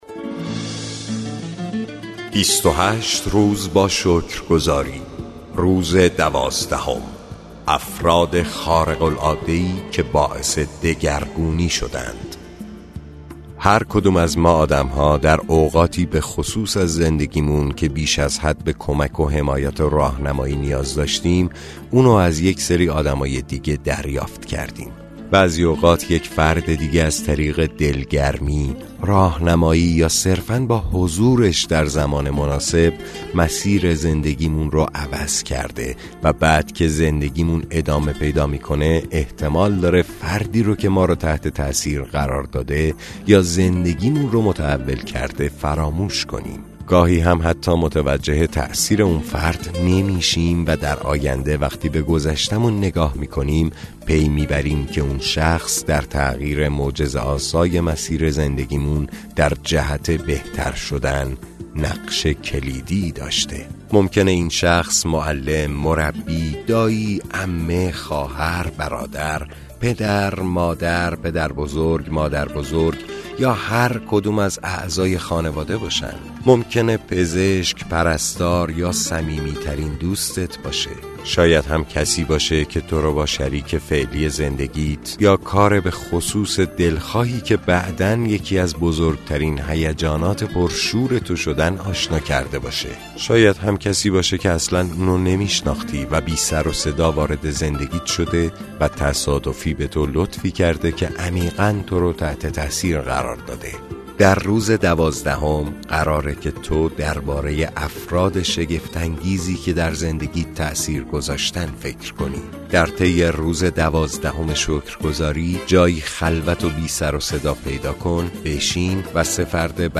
کتاب صوتی معجزه شکرگزاری – روز دوازدهم